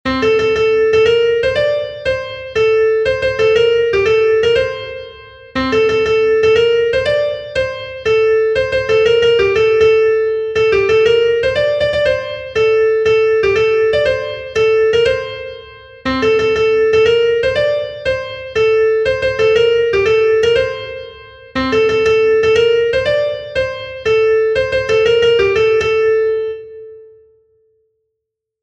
Sentimenduzkoa
Hamarreko handia (hg) / Bost puntuko handia (ip)
A-A-B-A-A